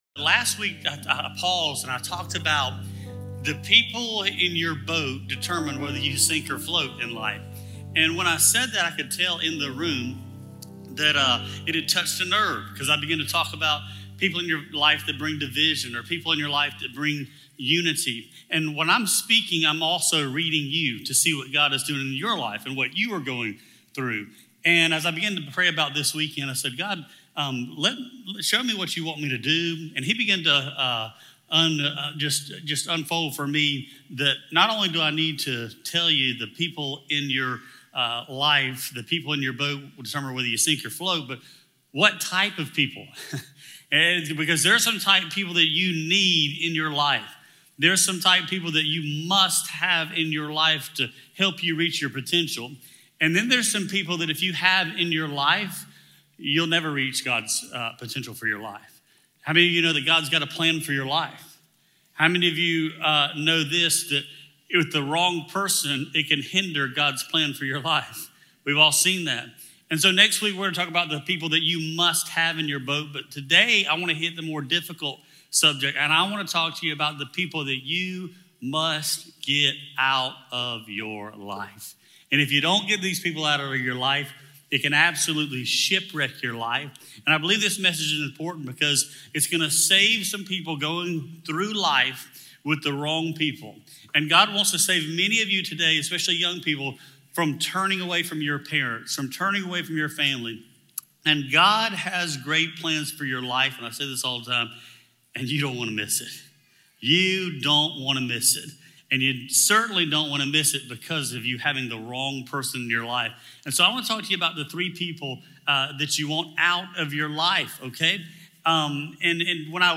special message